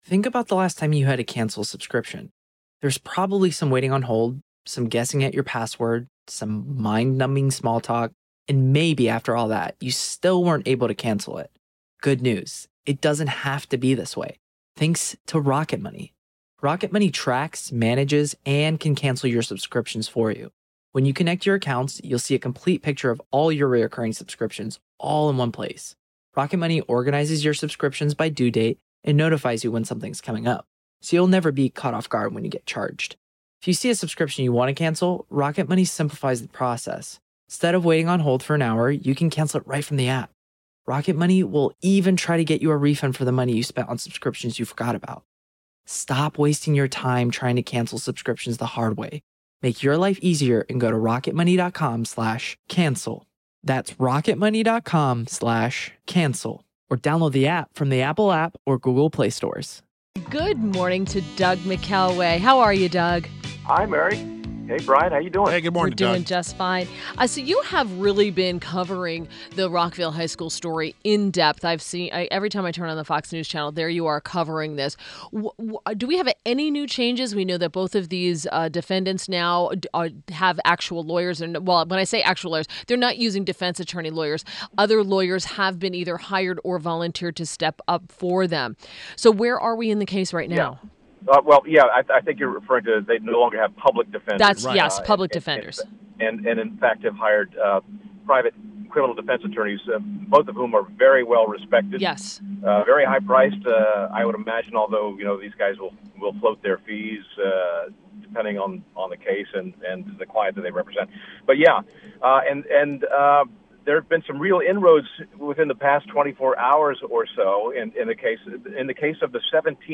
WMAL Interview - Doug McKelway - 03.29.17